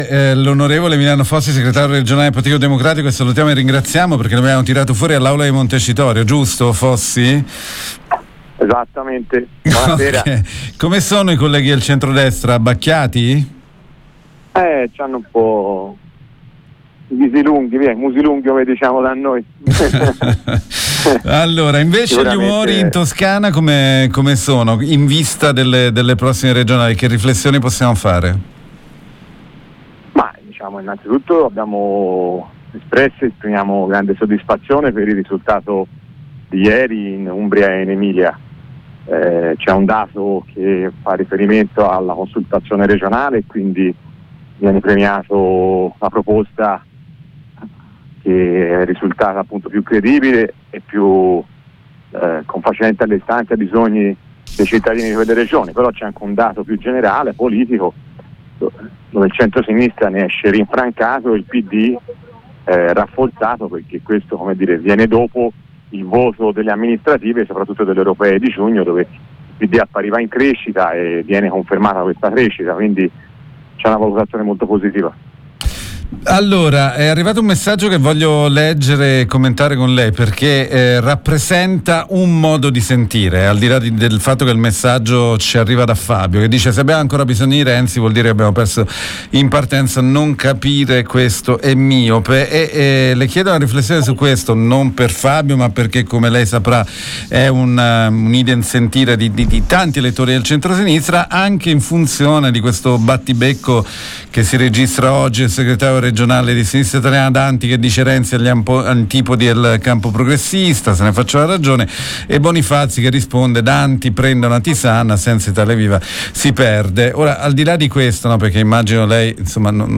FossiDopo la vittoria nelle regionali in Emilia Romagna e in Umbria abbiamo sentito il segretario regionale del Partito Democratico, Emiliano Fossi